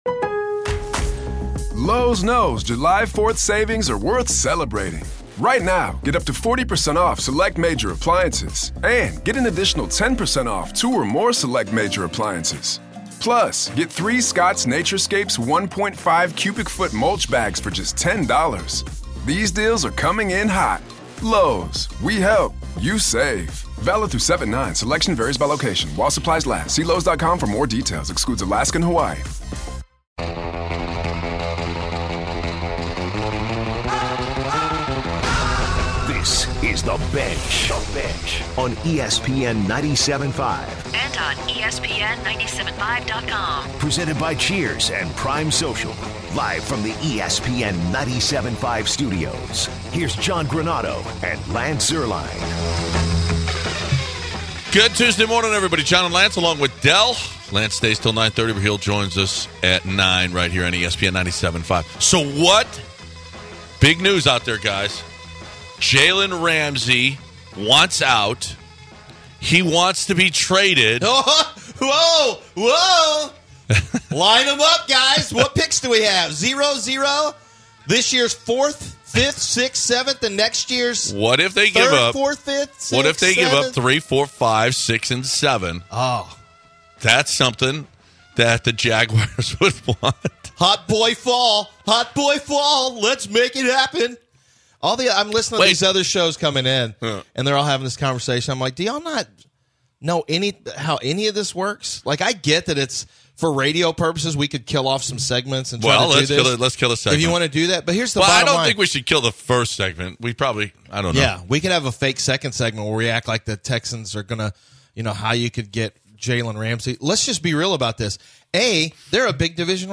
Head coach Jimbo Fisher of Texas A&M football joins the show talks success of the offense early in the season and the Aggies to face A&M this weekend. Pro athletes who can be high maintenance and require a lot of management. Discuss NFL Power Rankings.